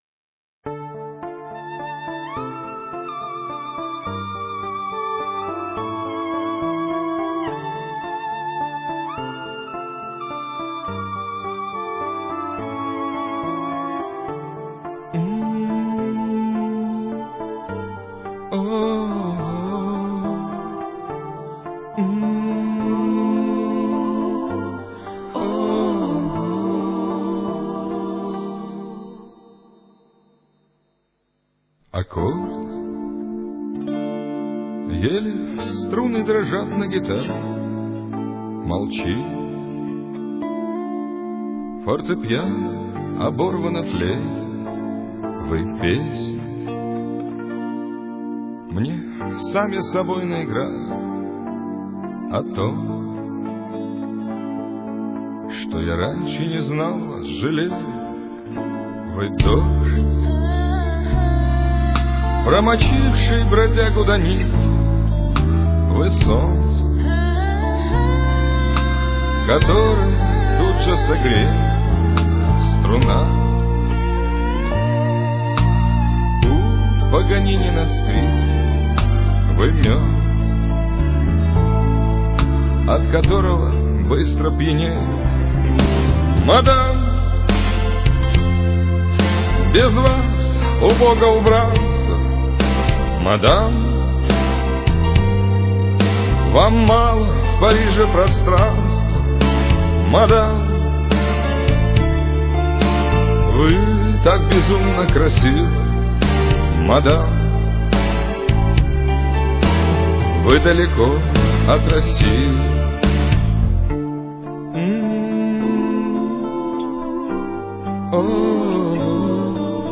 сорри за качество...